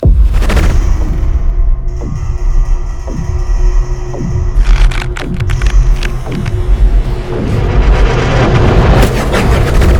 Звук коллапса блюспейс аномалии
Коллапс_блюспейс_аномалии.mp3